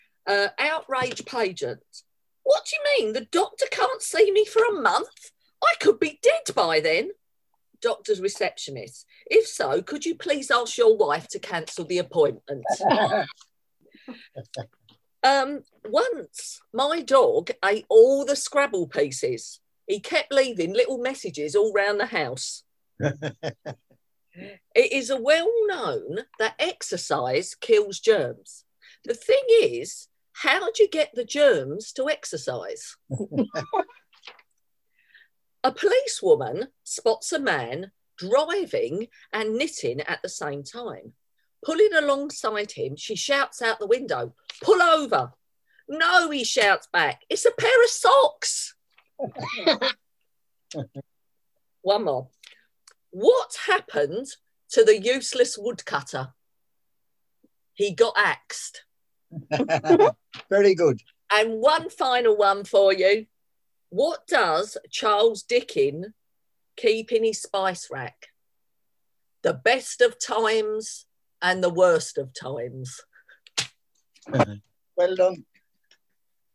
Reflections ads on Maritime Radio: